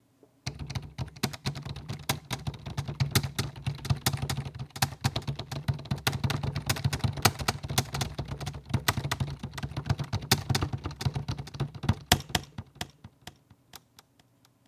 keyboard fast typing with accents delay
computer computer-keyboard delay fast key keyboard letters typing sound effect free sound royalty free Memes